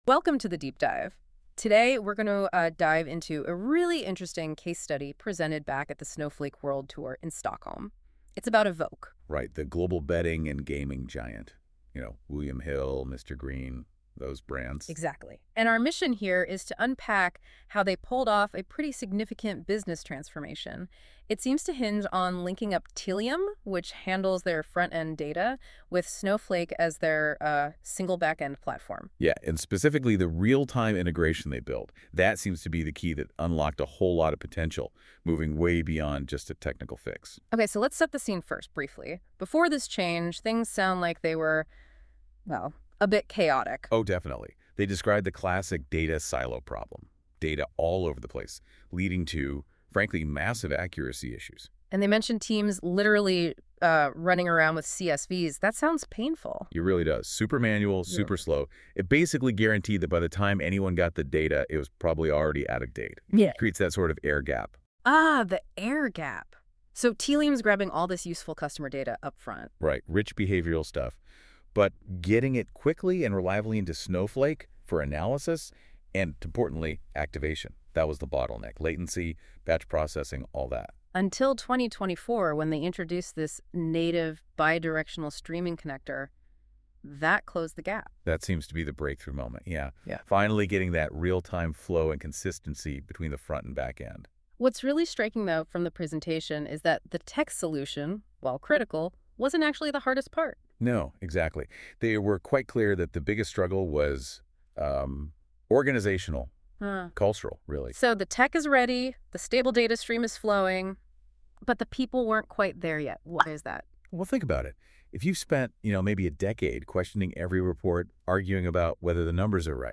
We used AI to generate this podcast.